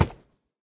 minecraft / sounds / mob / horse / wood2.ogg